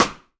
sidestick.wav